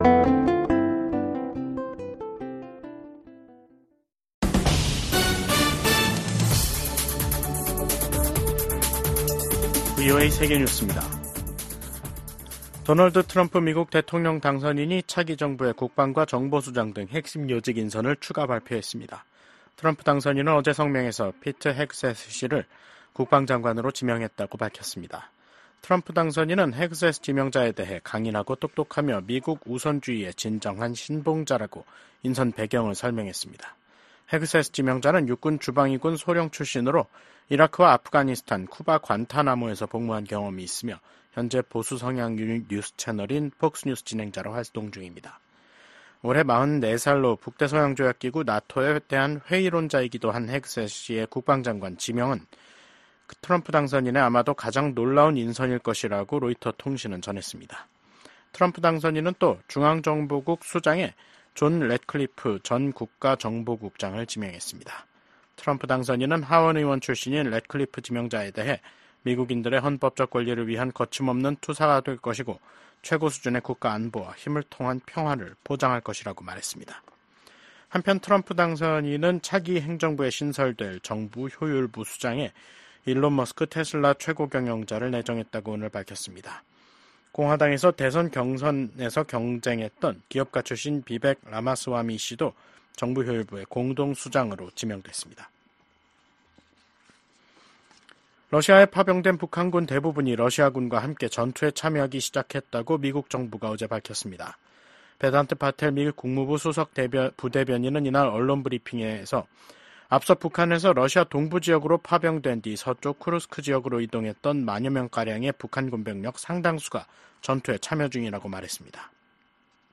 VOA 한국어 간판 뉴스 프로그램 '뉴스 투데이', 2024년 11월 13일 2부 방송입니다. 미국 백악관이 북한군의 러시아 파병을 공식 확인했습니다. 최소 3천명이 러시아 동부 전선에 파병됐으며 훈련 뒤엔 우크라이나와의 전투에 배치될 가능성이 있다고 밝혔습니다.